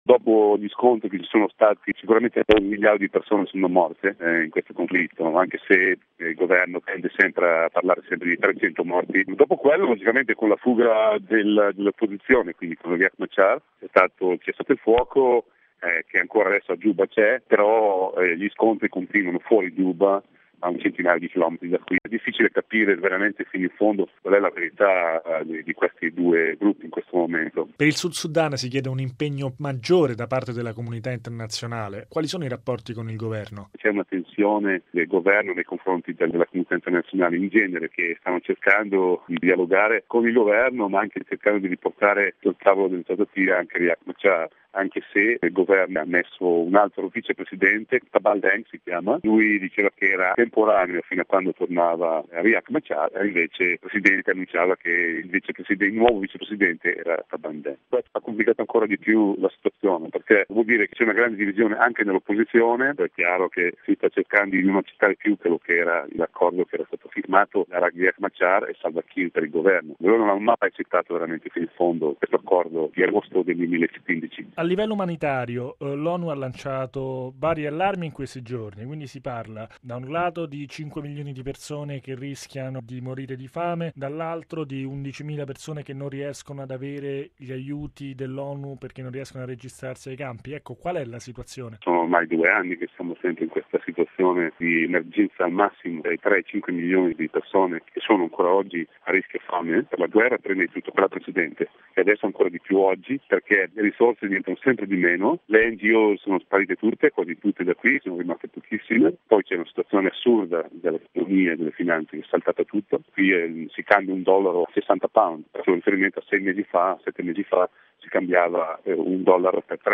Bollettino Radiogiornale del 04/08/2016